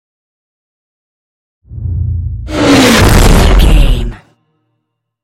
Scifi whoosh to hit 425
Sound Effects
dark
futuristic
intense
tension
woosh to hit